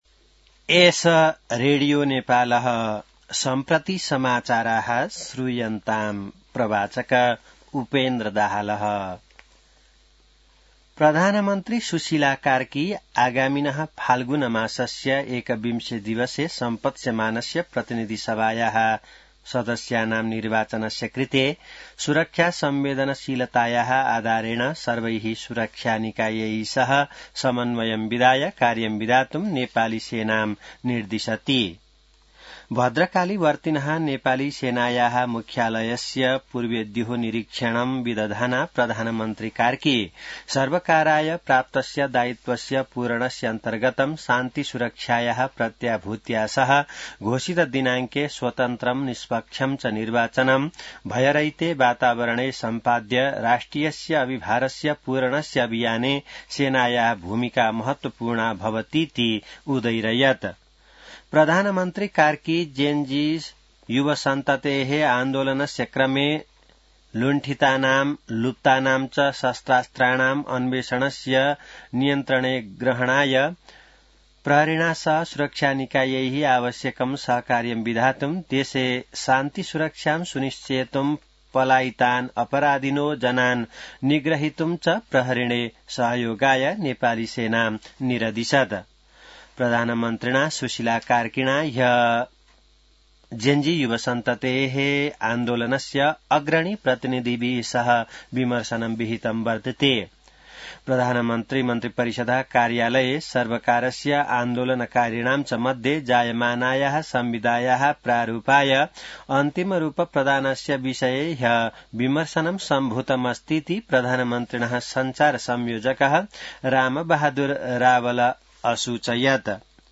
संस्कृत समाचार : १३ मंसिर , २०८२